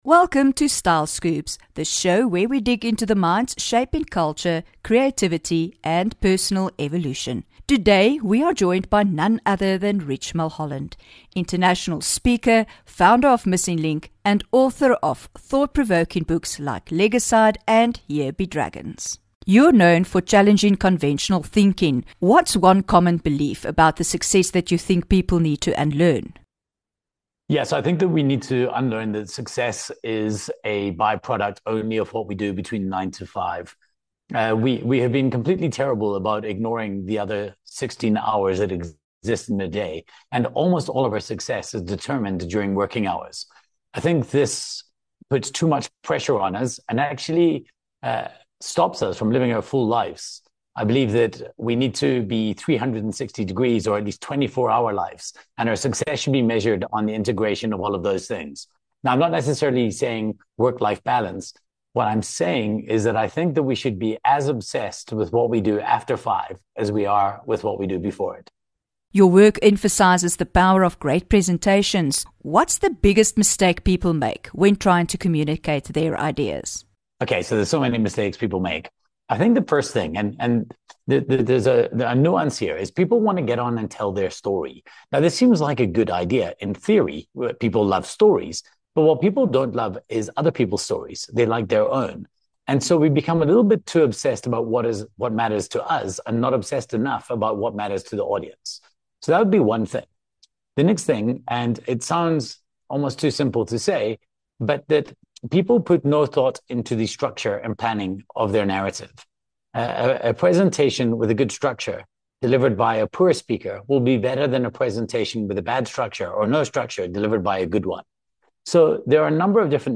9 Apr INTERVIEW